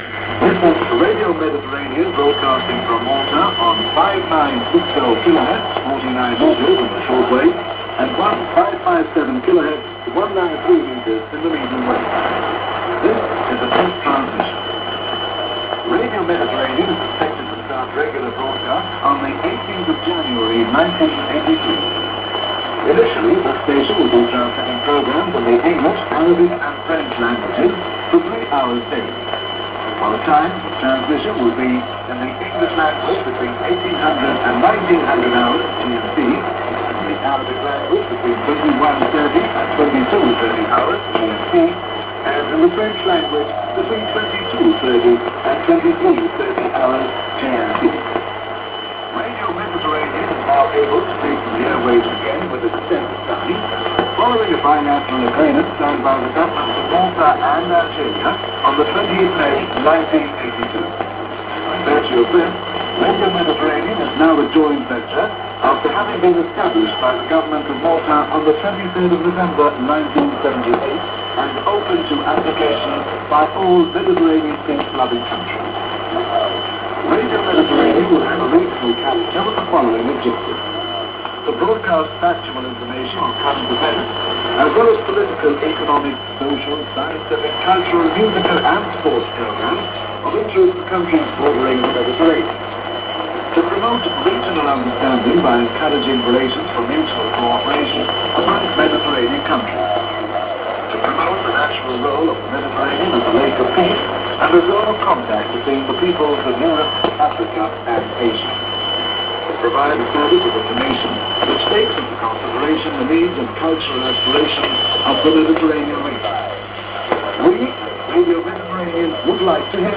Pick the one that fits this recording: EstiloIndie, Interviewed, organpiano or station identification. station identification